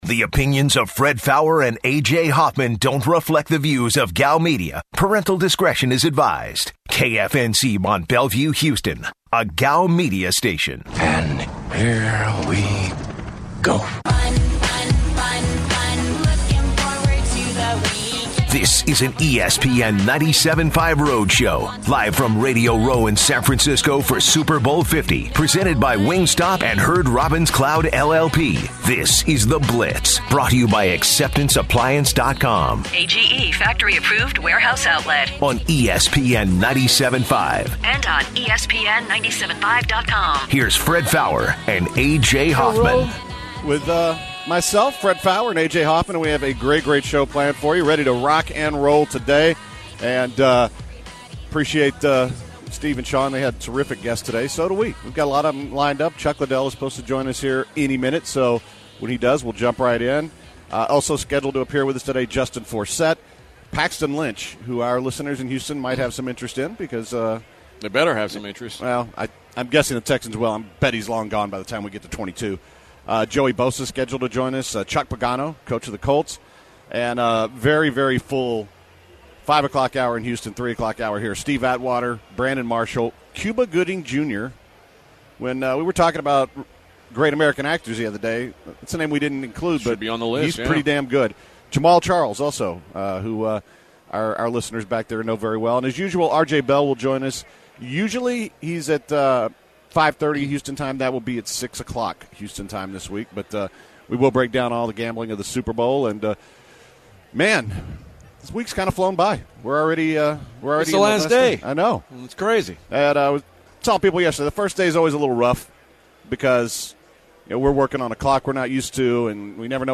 live from San Francisco, California. Former UFC star Chuck Liddell joins the guys to discuss the youth movement in the UFC, Conor McGregor, and Super Bowl 50. Baltimore Ravens running back Justin Forsett joins the Blitz to address his recovery from injury last season, Gary Kubiak as his former coach, and the water situation in Flint, Michigan.